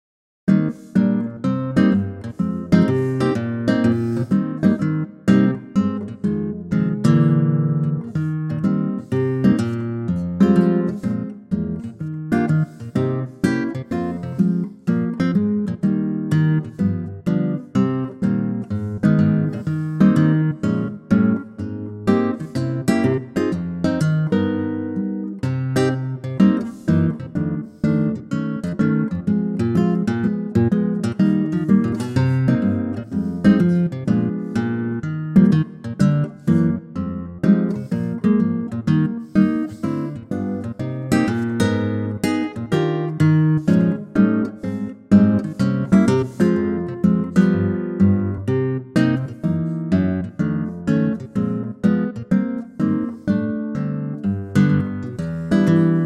key - Eb - vocal range - Bb to D
A superb acoustic guitar arrangement
perfect for an intimate vocal or intimate instrumental.